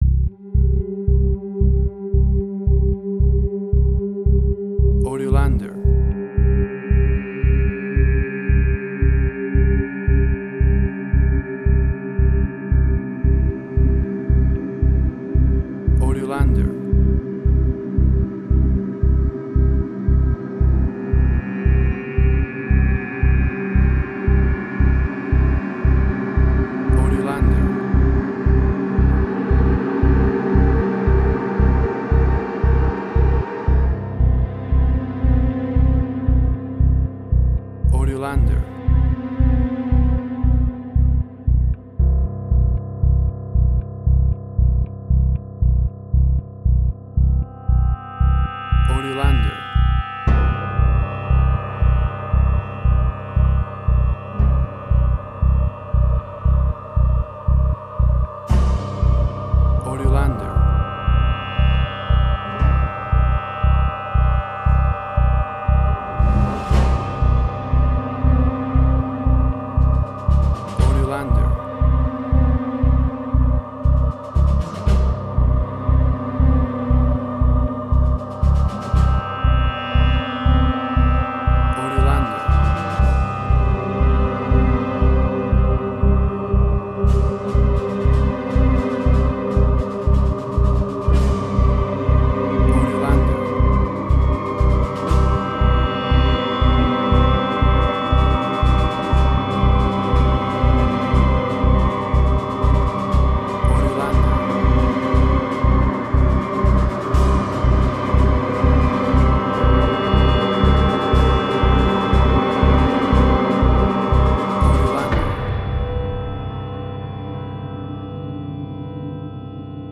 Dissonance
Tempo (BPM): 117